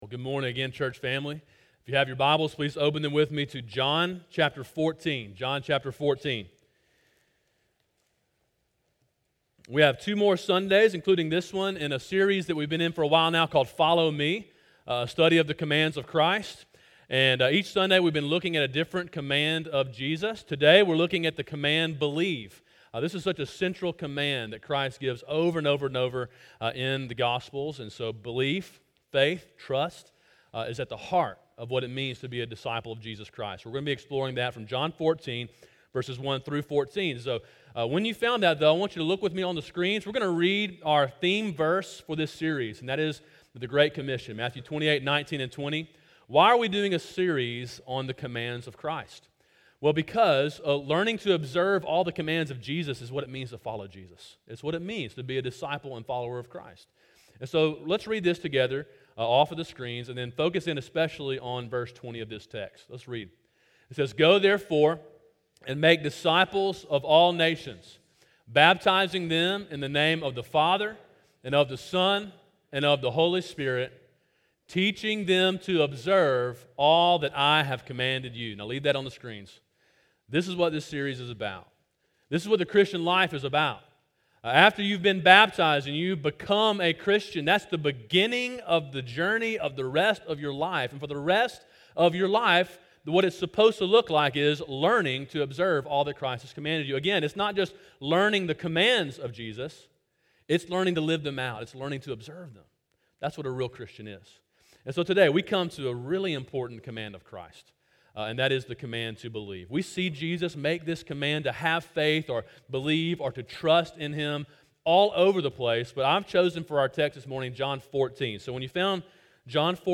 Sermon: “Believe” (John 14:1-14) – Calvary Baptist Church